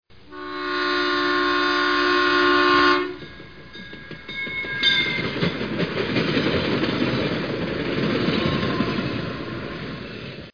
Download Trains sound effect for free.
Trains